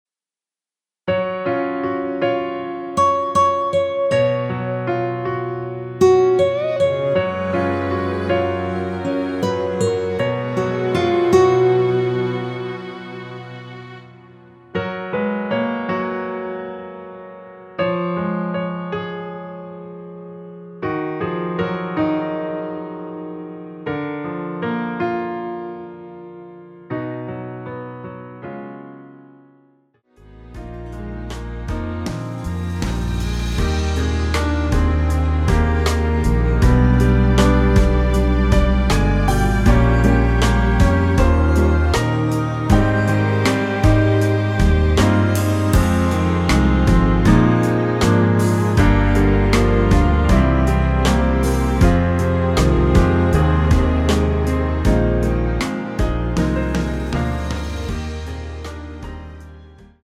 F#
◈ 곡명 옆 (-1)은 반음 내림, (+1)은 반음 올림 입니다.
앞부분30초, 뒷부분30초씩 편집해서 올려 드리고 있습니다.